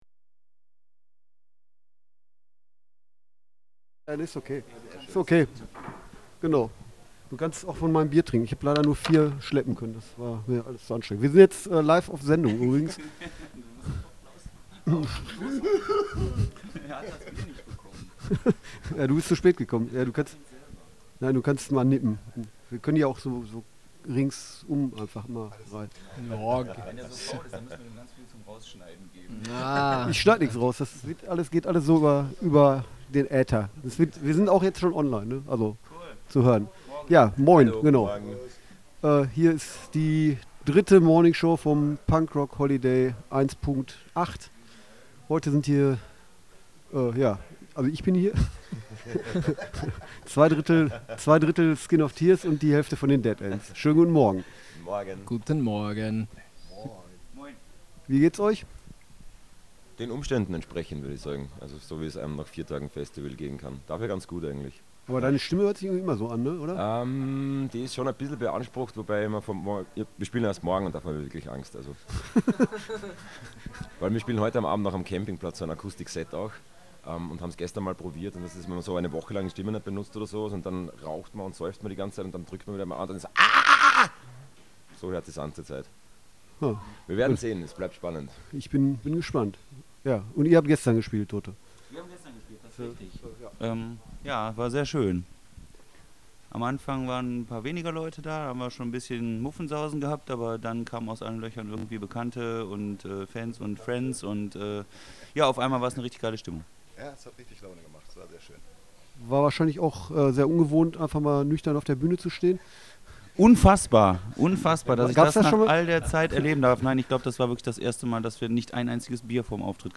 Letzte Episode Morning Show vom Punk Rock Holiday 1.8 mit Skin Of Tears und Deadends 8. August 2018 Nächste Episode download Beschreibung Teilen Abonnieren Bei der Morning Show am Donnerstag waren gleich zwei Bands zu Besuch.